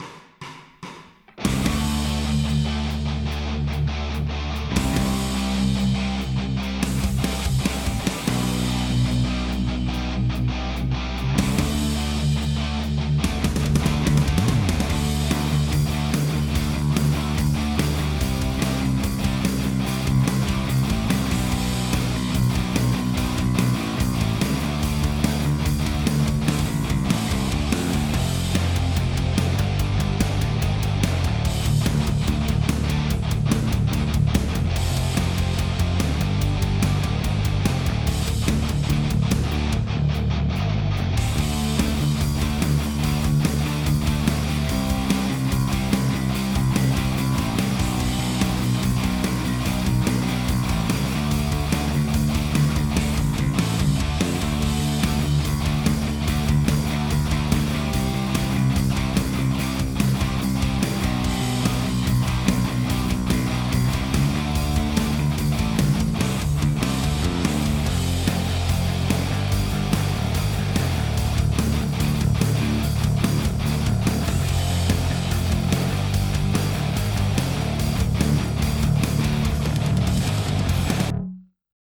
Backing track for 20th Anniversary contest